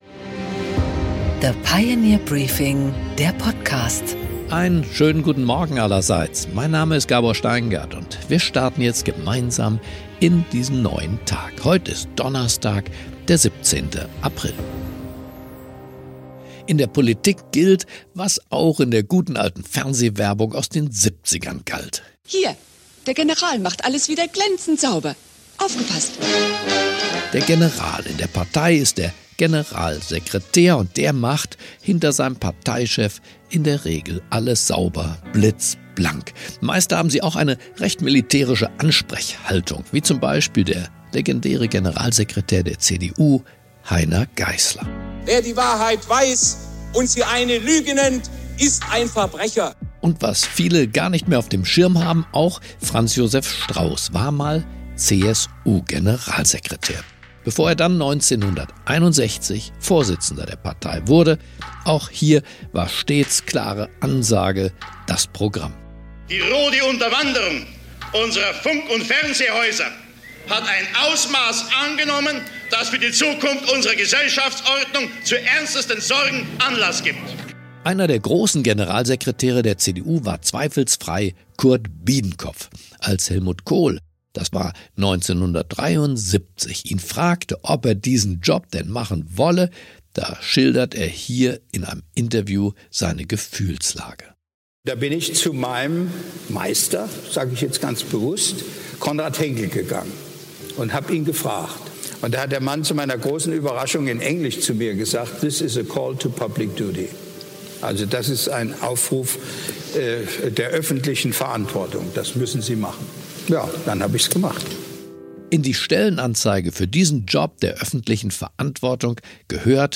Täglich ab 6 Uhr mit exklusiven Interviews, Berichten und Kommentaren der The Pioneer-Redaktion zum politischen und wirtschaftlichen Weltgeschehen.